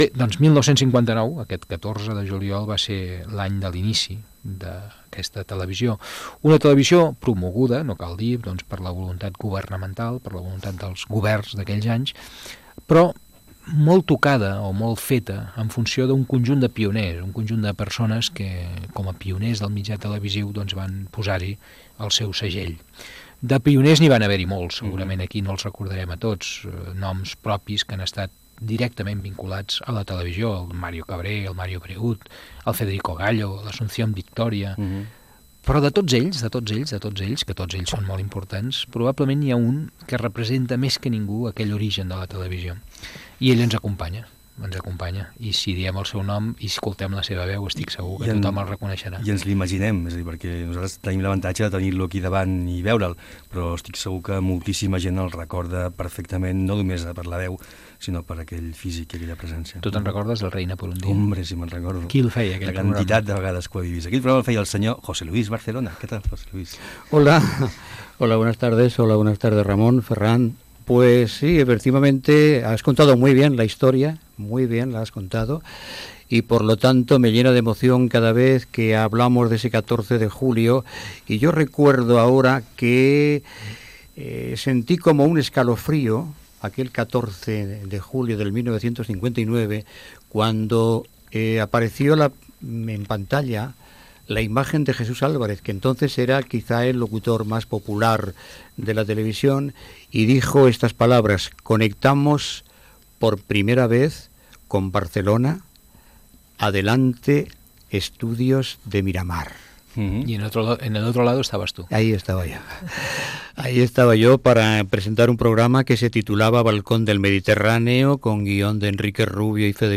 Fragment d'una entrevista al presentador José Luis Barcelona.